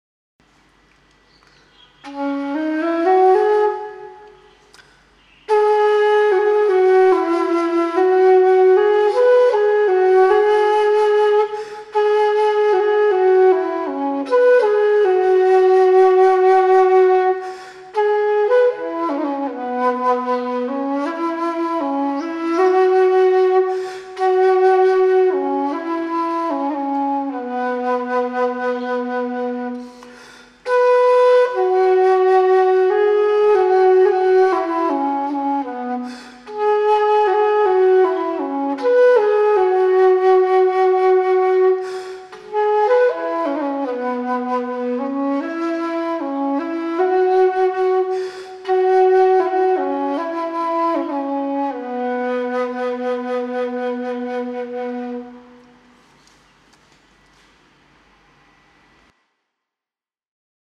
本人目前用MOTU UliraLiteMk3声卡和老797生产的RODE NT2000的话筒，业余录民族吹奏乐器，目前的问题，录出的音色怎么都达不到我直接听的箫的音色，怀疑是不是话放质量达不到，现在准备两个方案，第一，买二手声卡（RME FF800和TC K48里面选择）稳定性不议，因为我用的MAC ，火线应该不存在K48的兼容问题，现在咸鱼二手价格两个声卡一致都是4千。
我家里自己录，肯定没有什么声学装修，只是做了吊顶
附上我录的两个给老师们听下，有个很奇怪的现象，第一个是F调的箫，第二个是E调的箫，我耳朵直接听，箫的音色都很好，但是F调的箫用同样位置，同样方法录制，音色差了很多，而且我也试过，带耳机监听然后移动吹奏位置，在特定位置，用耳机听某个音符会变成很窄的频率，这个离麦克风越近越明显，不知道是什么原因！